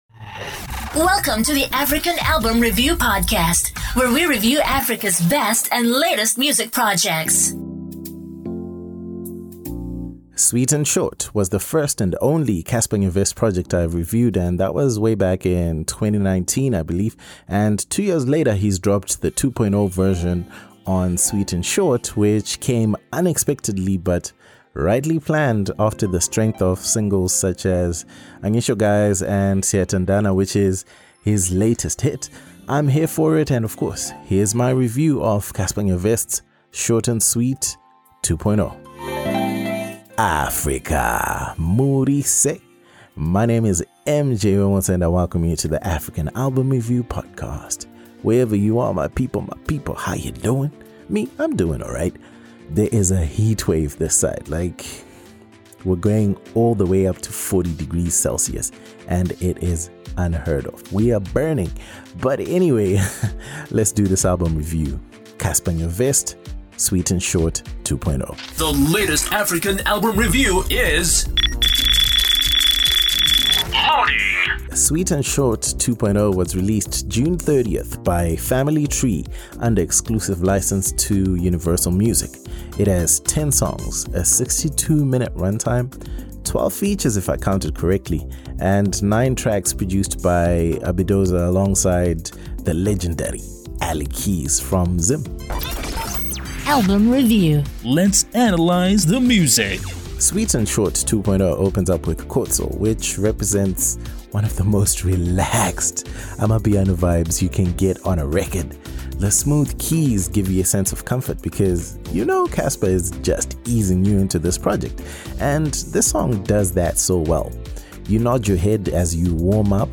Cassper Nyovest – Sweet and Short 2.0 ALBUM REVIEW South Africa ~ African Album Review Podcast